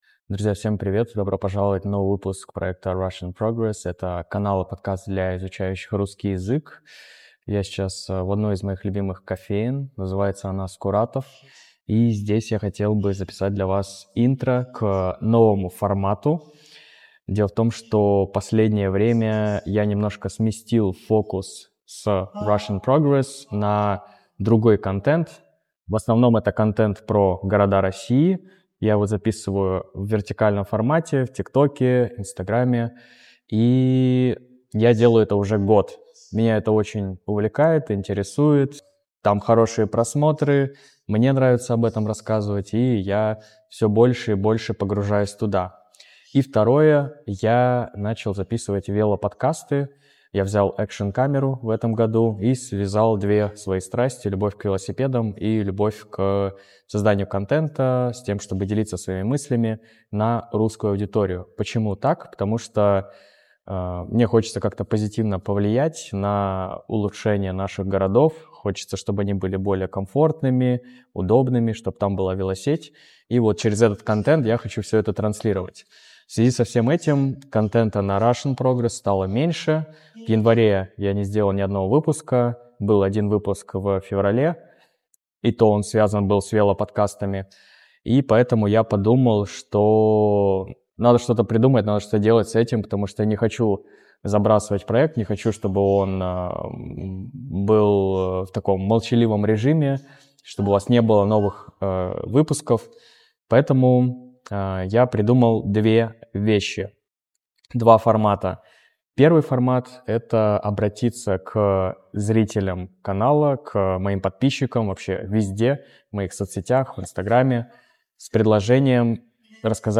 Я сейчас в одной из моих любимых кофеен.